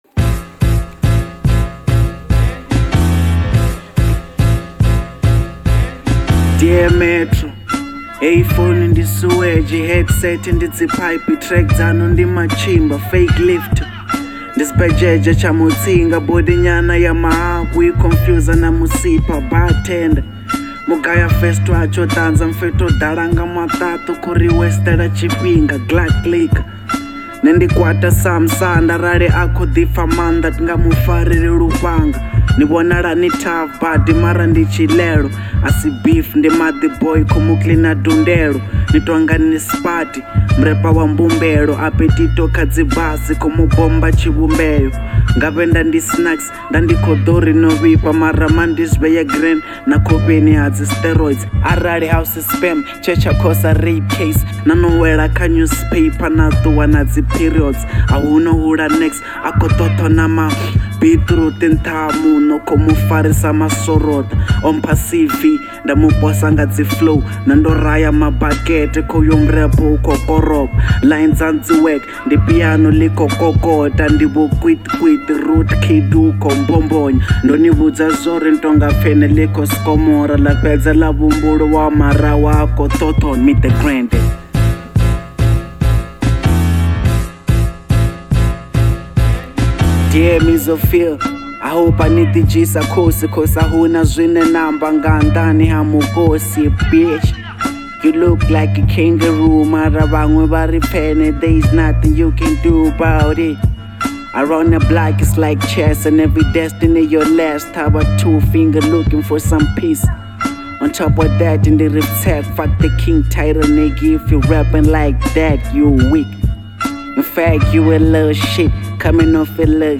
06:32 Genre : Venrap Size